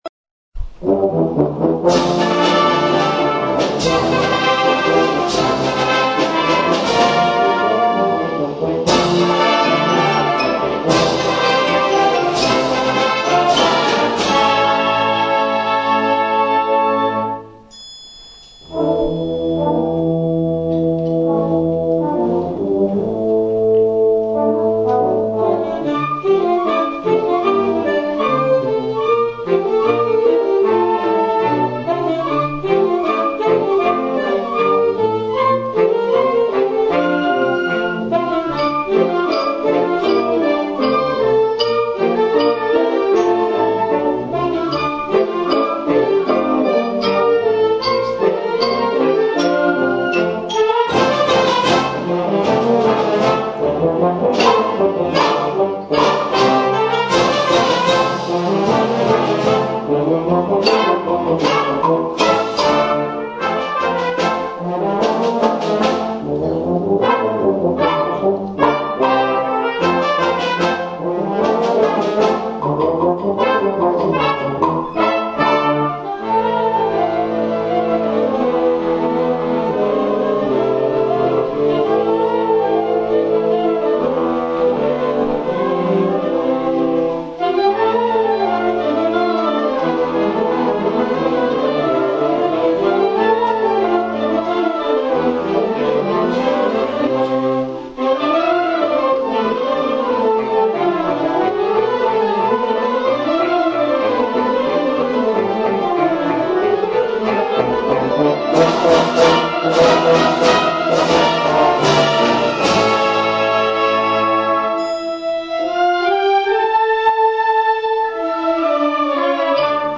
Genere Polka Formazione Harmonie